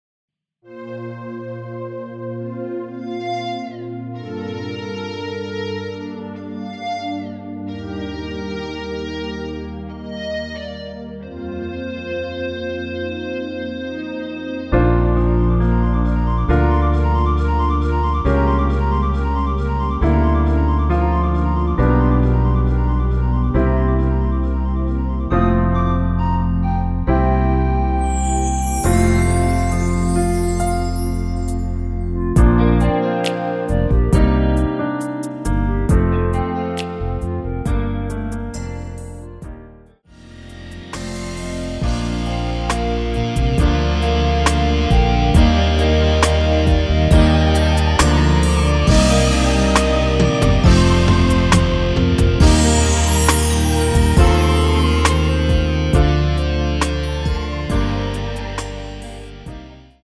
Bb
멜로디 MR이라고 합니다.
앞부분30초, 뒷부분30초씩 편집해서 올려 드리고 있습니다.